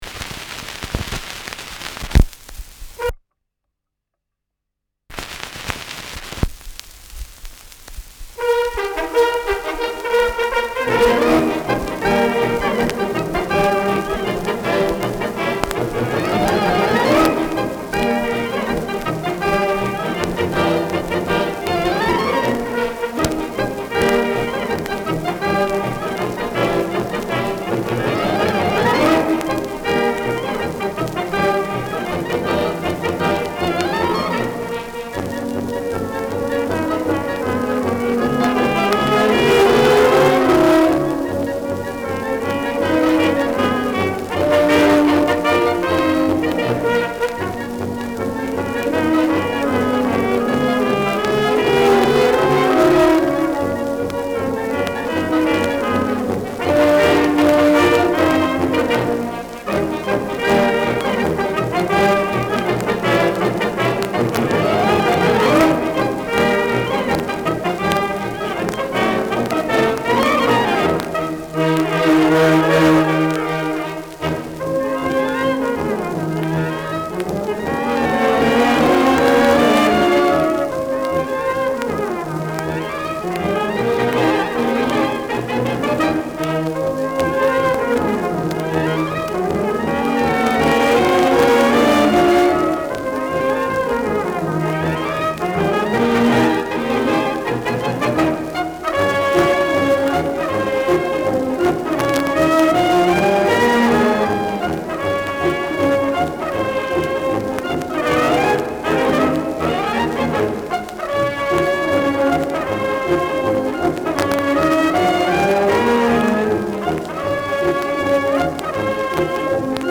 Schellackplatte
Durchgehend leichtes Knacken : Leicht abgespielt